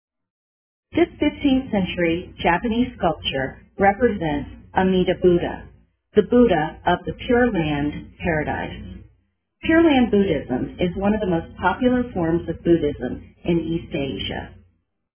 Audio Tour – Ear for Art